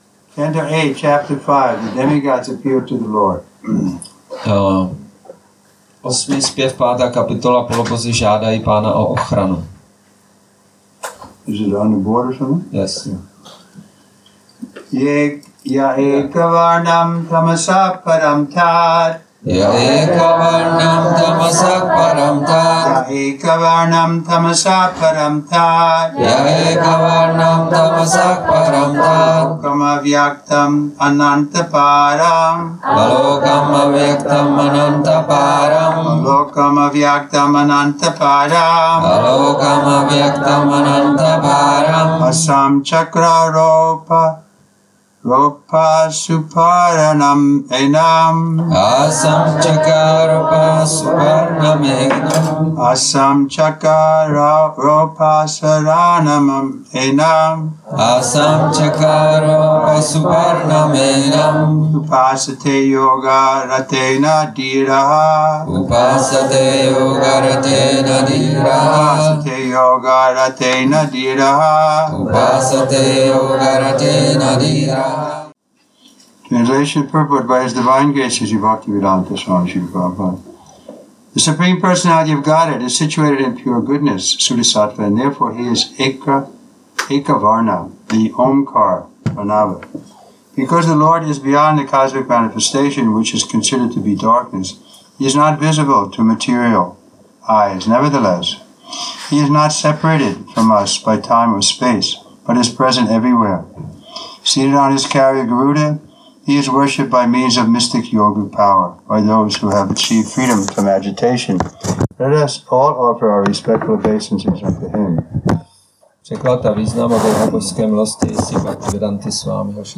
Přednáška SB-8.5.29 – Šrí Šrí Nitái Navadvípačandra mandir